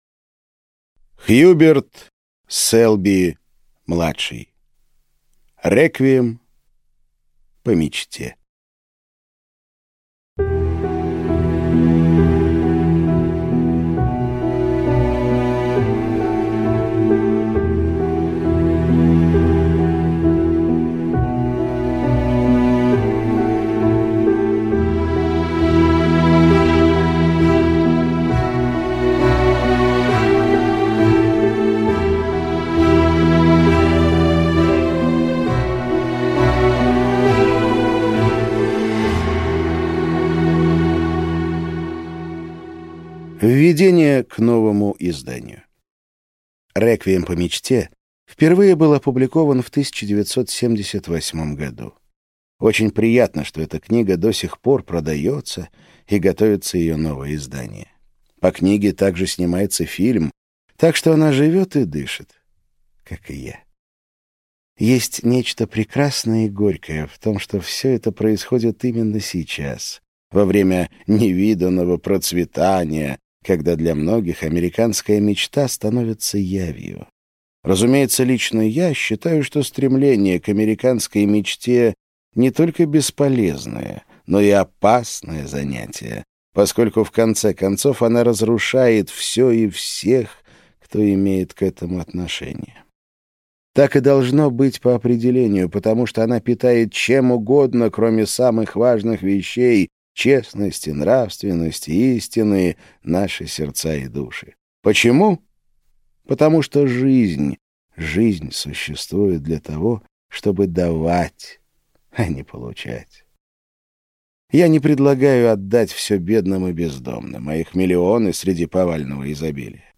Аудиокнига Реквием по мечте | Библиотека аудиокниг